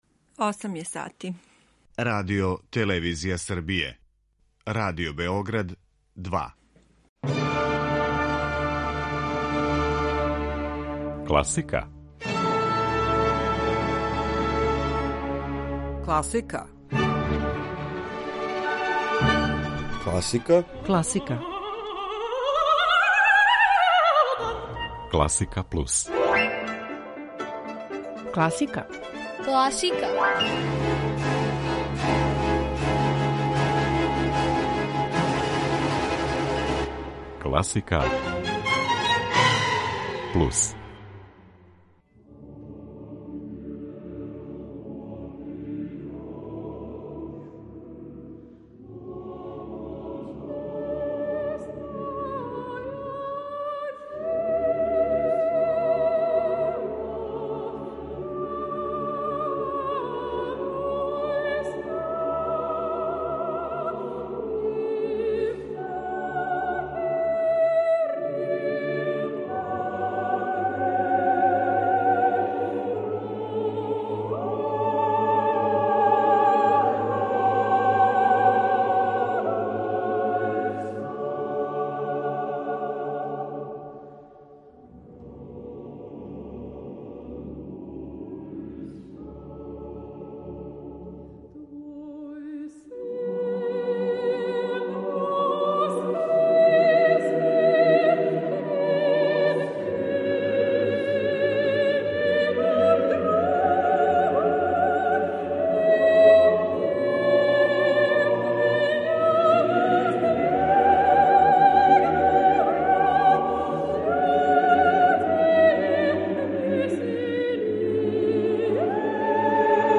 У данашњој емисији, међутим, емитоваћемо неколико вокалних и вокално-инструменталних дела која су, инспирисани великим празником, написали Римски Корсаков, Иван Муди, Александар Глазунов, Јосиф Маринковић.